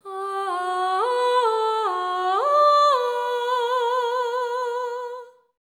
ETHEREAL12-R.wav